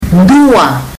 dua One ndoo ah